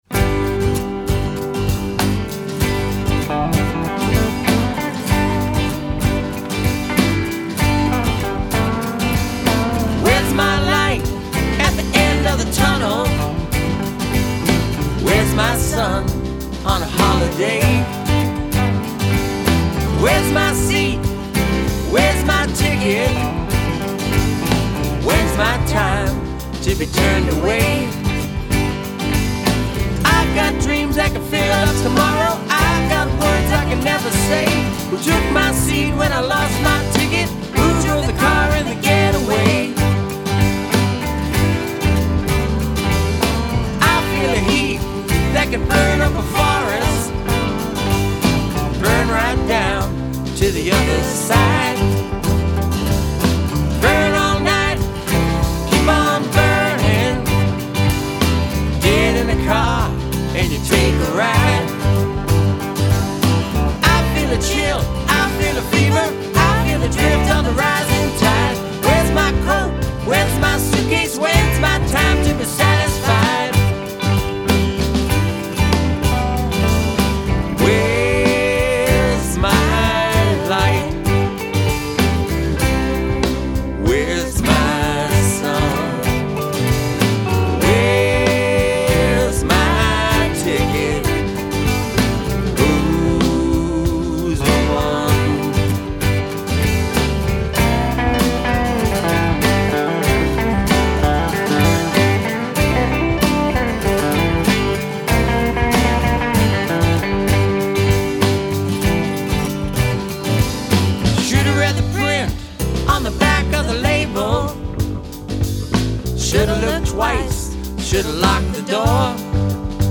Guitars and Vocals
Drums
Keyboards